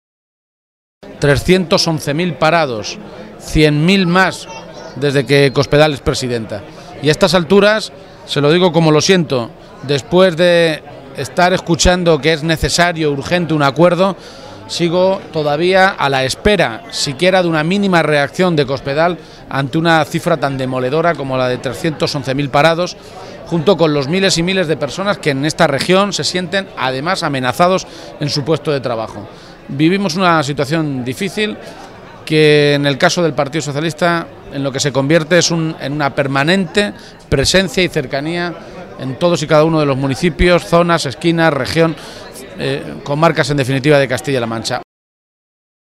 García-Page resaltó este compromiso en un encuentro comarcal celebrado en el municipio ciudadrealeño de La Solana, precisamente una comarca que si se cumplen las intenciones del Gobierno regional sufrirá las consecuencias de la privatización del hospital de referencia de Manzanares.